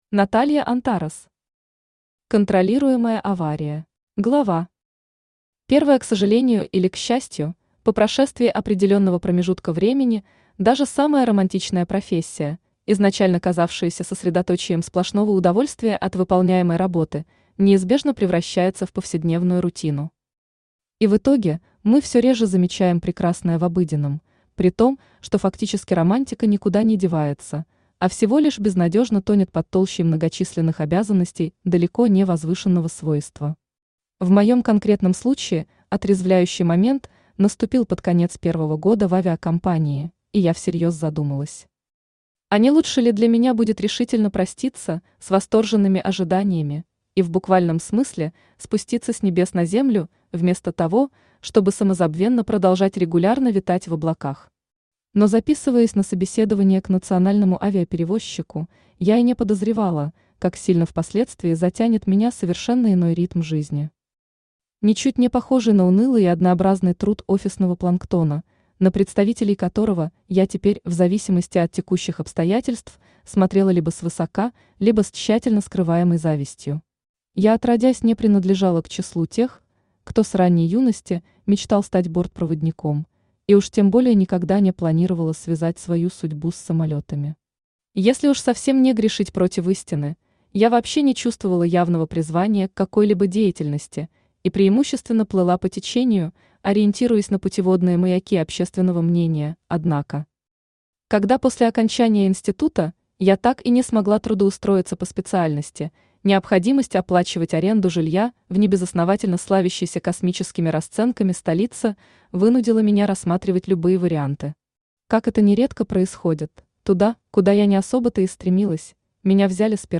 Аудиокнига Контролируемая авария | Библиотека аудиокниг
Aудиокнига Контролируемая авария Автор Наталья Антарес Читает аудиокнигу Авточтец ЛитРес.